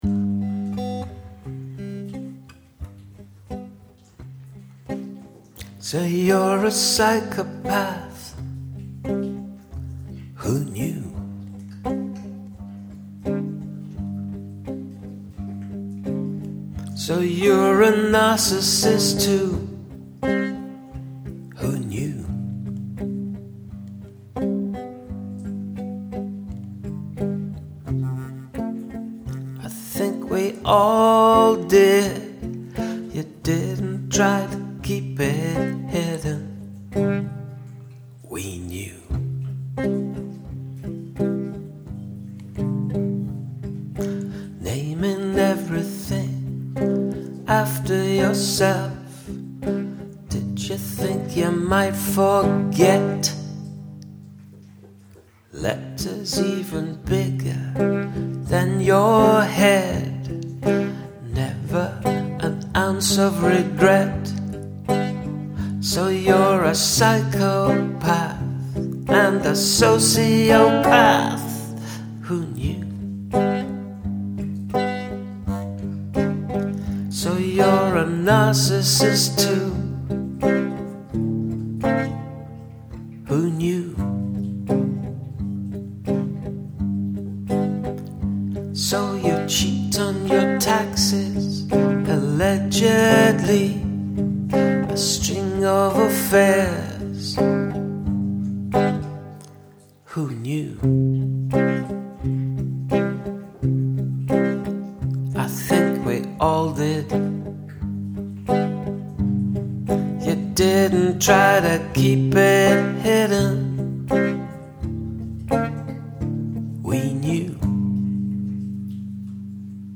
the whispered "we knew" is so pointed!
Cool guitar tone and muted strings I think - great sound. It leaves great space when you pause since it is not ringing out.
Great vocals and phrasing - the message comes through loud and clear.